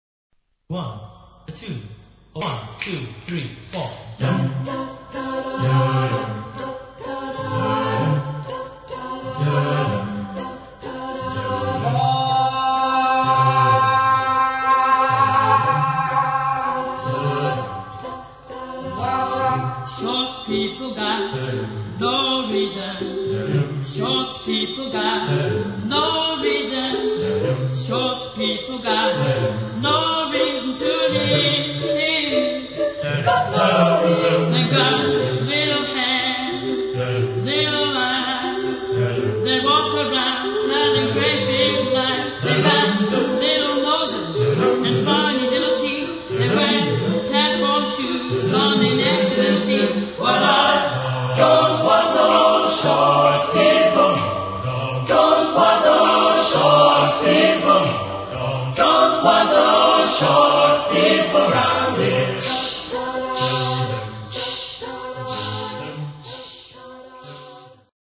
Genre-Stil-Form: Rock ; Unterhaltungsmusik ; weltlich
Chorgattung: SSAATTBB  (8 gemischter Chor Stimmen )
Tonart(en): C-Dur